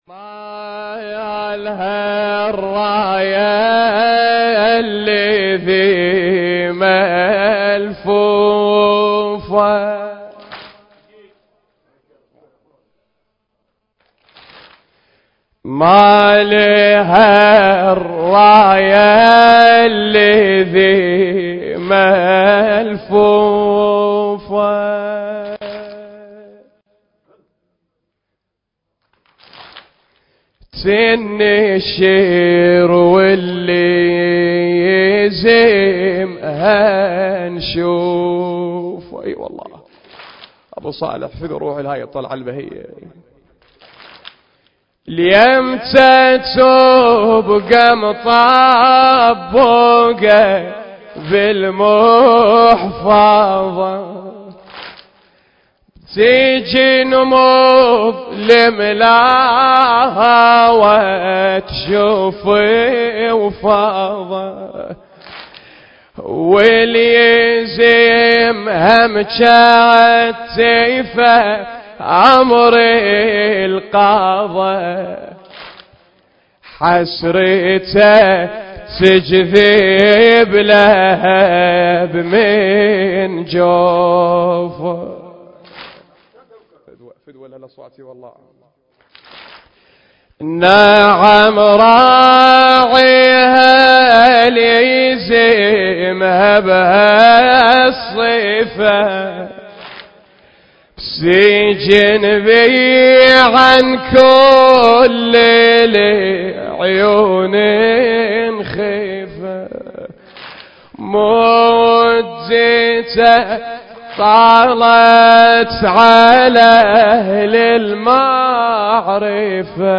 المكان: حسينية أمير المؤمنين عليه السلام/ طهران